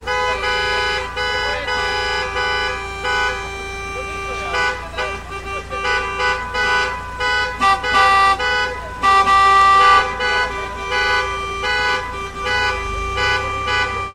На этой странице собраны звуки автомобильной пробки — гудки машин, шум двигателей и общая атмосфера затора.
Шум хаоса на дорогах (множество клаксонов)